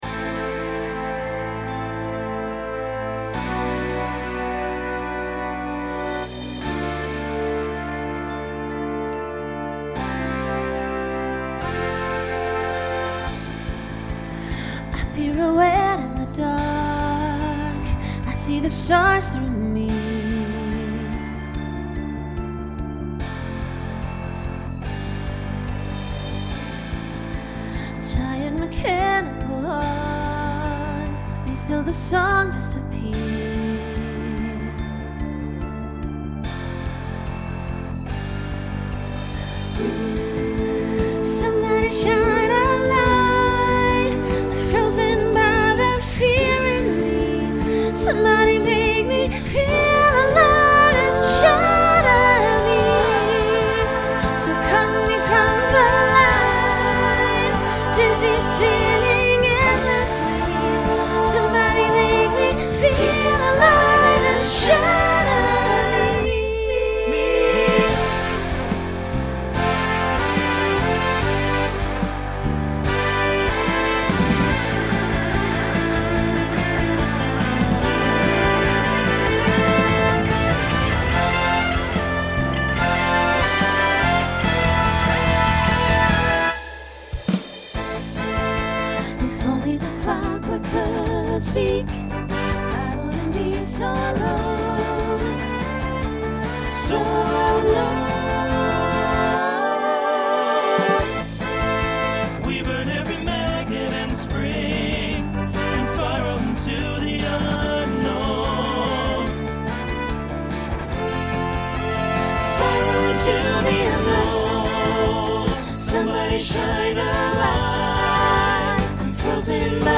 Genre Pop/Dance Instrumental combo
Solo Feature Voicing SATB